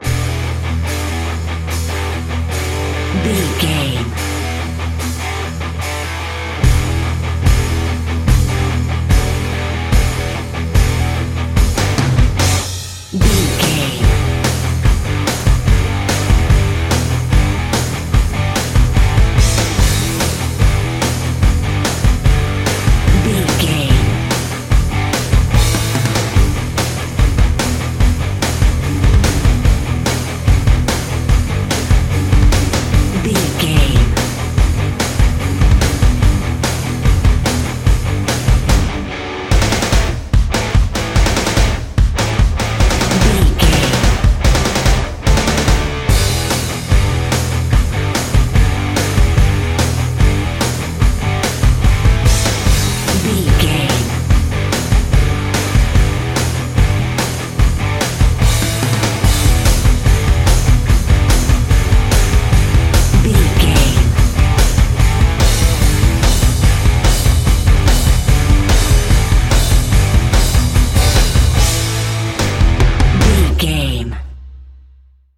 Epic / Action
Aeolian/Minor
Fast
drums
electric guitar
bass guitar
hard rock
aggressive
energetic
intense
nu metal
alternative metal